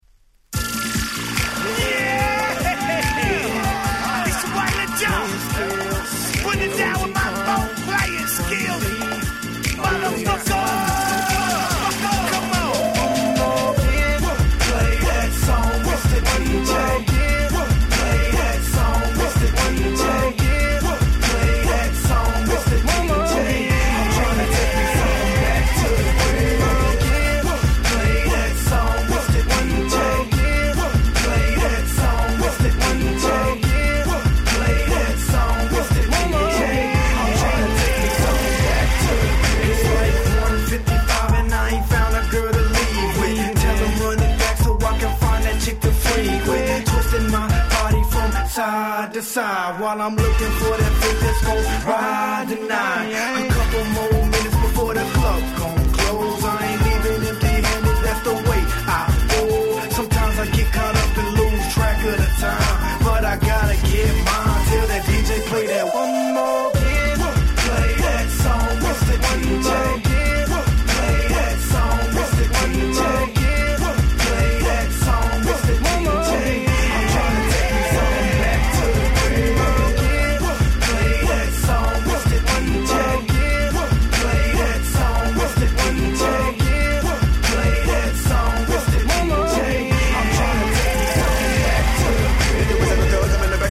09' Smash Hit Hip Hop !!